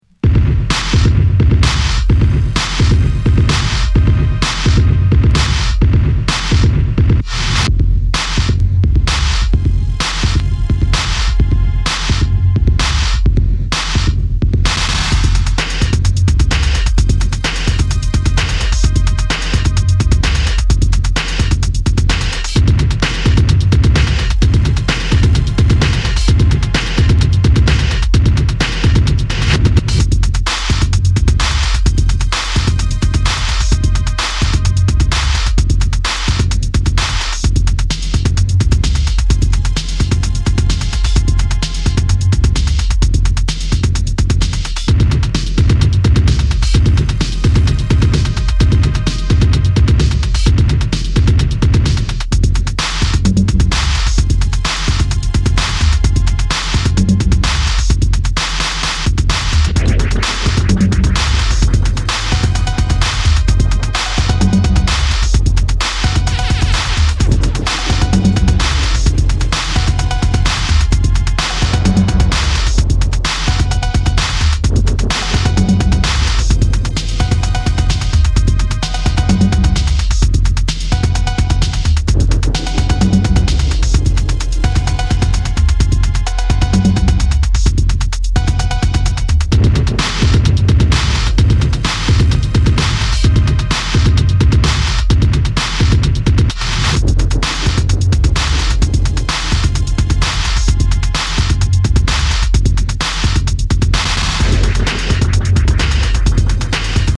尖ったインダストリアル・ビート主体ながらエレクトロ、テクノ、ミニマルをもACID感覚も絡めつつ突き進む狂気の全13曲！